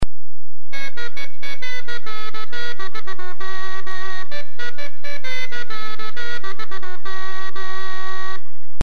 CRUMHORN
Crumhorn Sound Clips